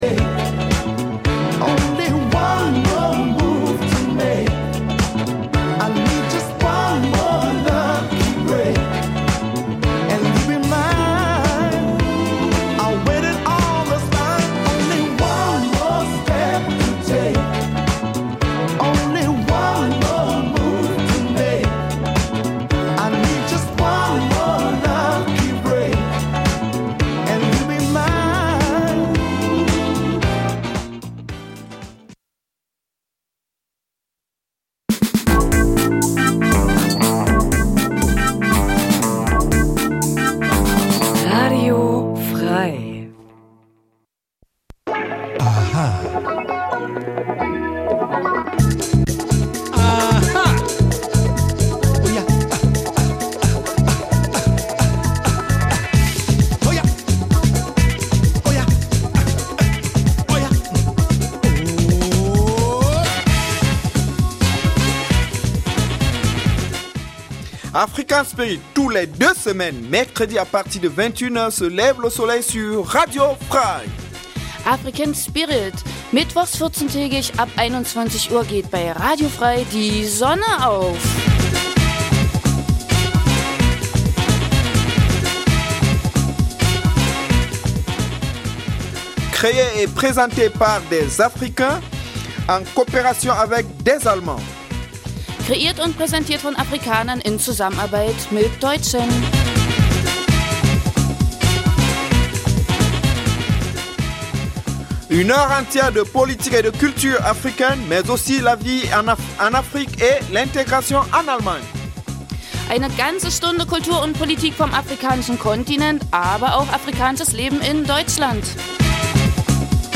Speziell versuchen wir die Berührungspunkte zwischen Afrika und Thüringen zu beleuchten. In einem Nachrichtenblock informiert die Sendung über die aktuelle politische Situation vor allem aus Afrikas Krisengebieten, aber auch über die Entwicklung der Zuwanderungs-politik in Deutschland welche das Leben vieler Afrikaner bei uns beeinflußt.
Afrikanisches zweisprachiges Magazin Dein Browser kann kein HTML5-Audio.
Die Gespräche werden mit afrikanischer Musik begleitet.